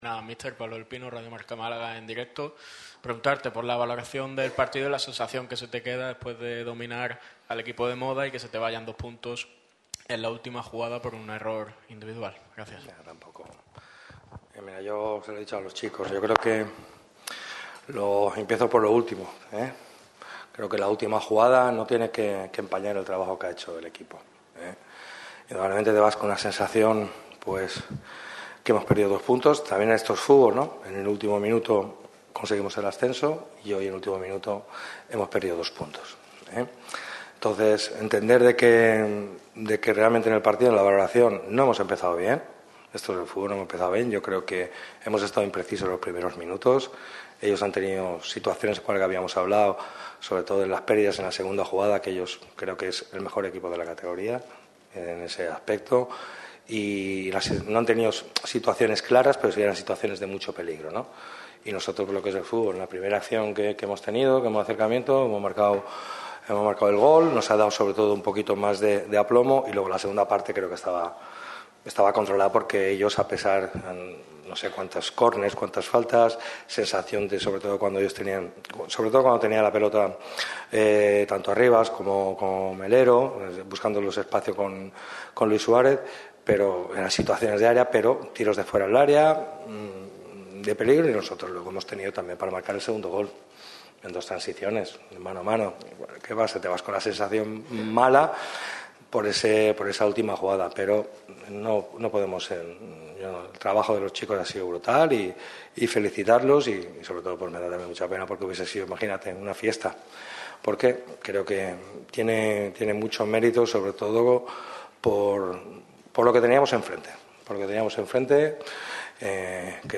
El entrenador del Málaga CF ha comparecido ante los medios de comunicación al término del encuentro que les ha enfrentado contra la UD Almería en La Rosaleda.